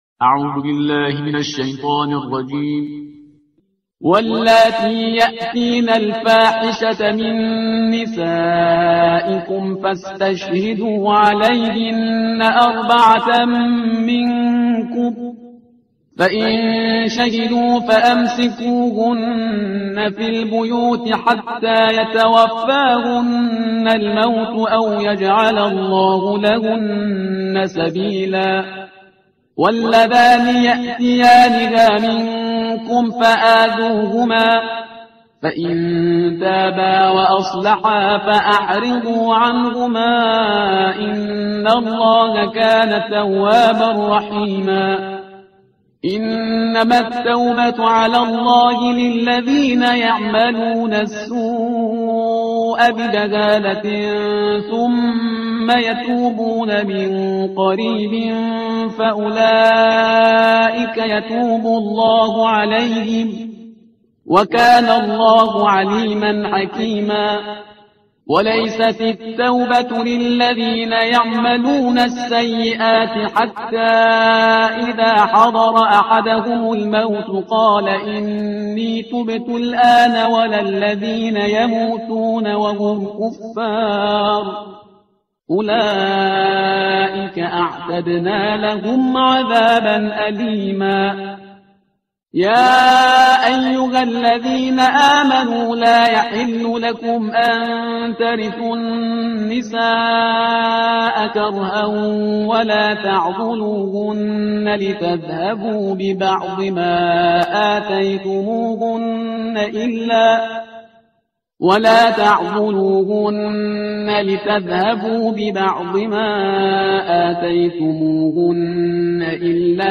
ترتیل صفحه 80 قرآن با صدای شهریار پرهیزگار